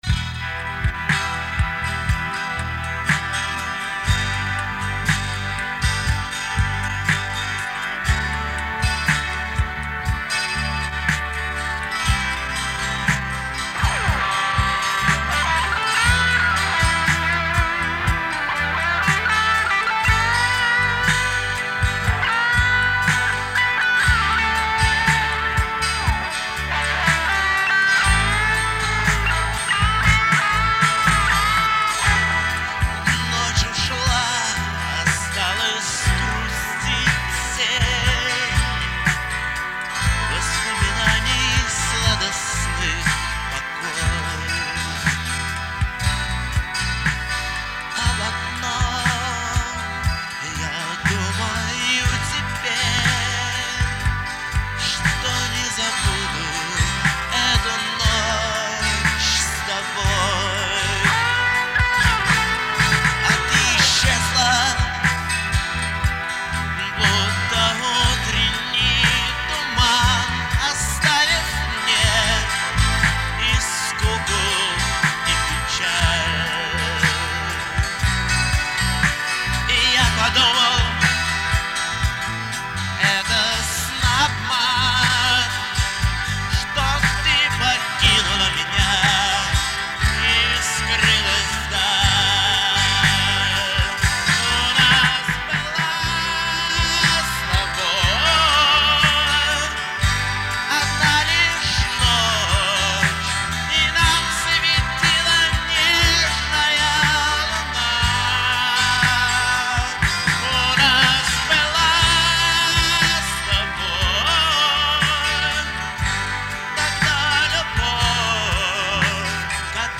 Сборник пробных записей, этюдов, репетиций
музыка, текст, вокал, гитары
барабаны, перкуссия, драм-машина
бэк-вокал, бас-гитара
клавиши (фортепиано, vermona, электроника)